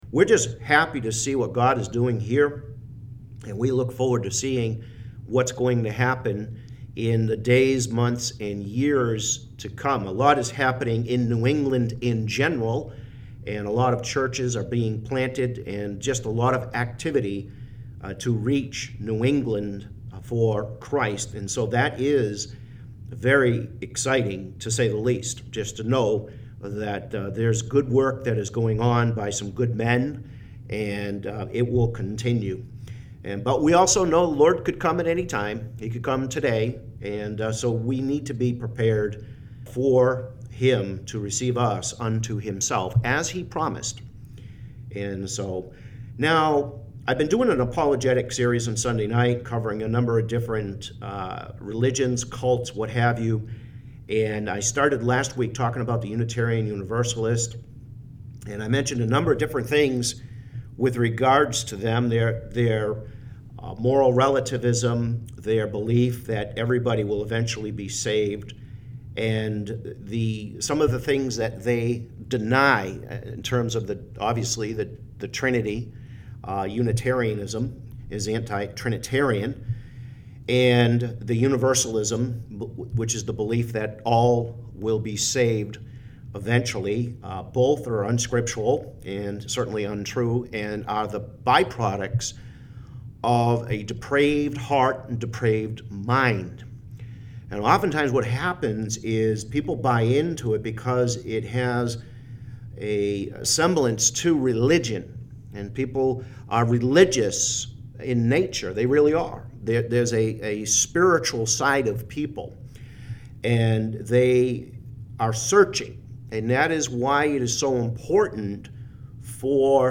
Just an edited version of the radio program with some musings on Unitarian Universalist.